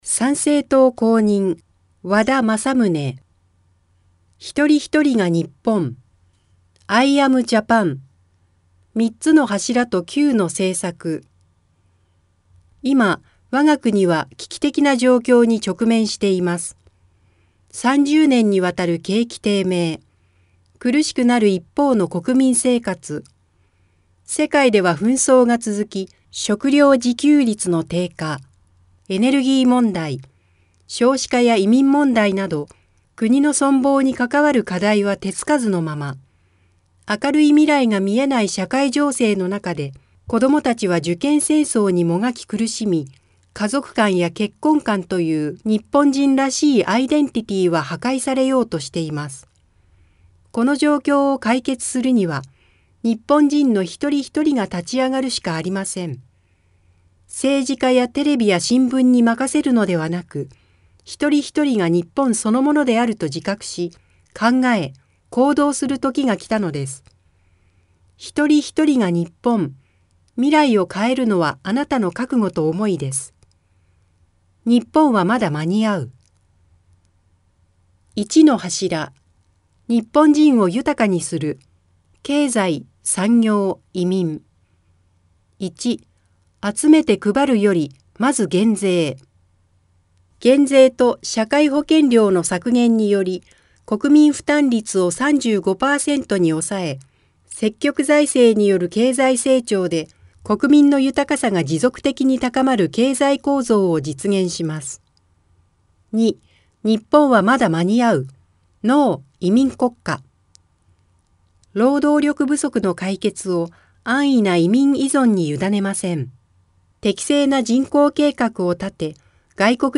衆議院議員総選挙候補者・名簿届出政党等情報（選挙公報）（音声読み上げ用）